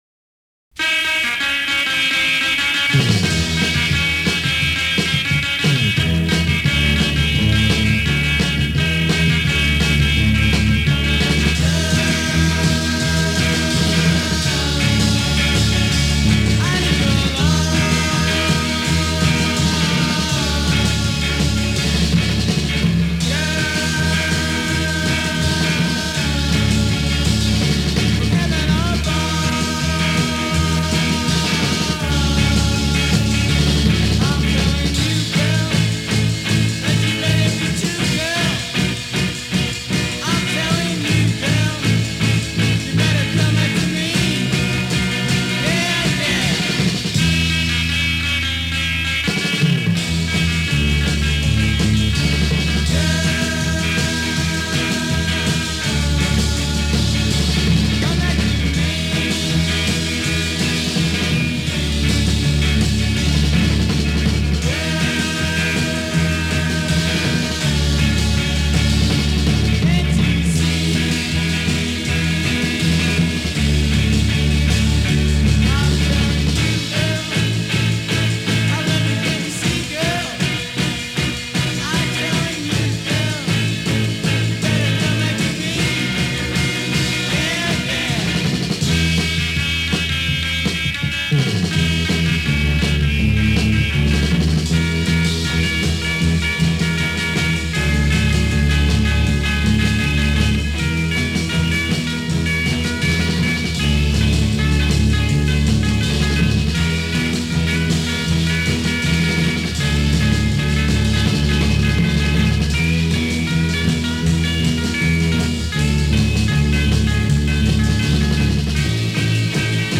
great garage rockers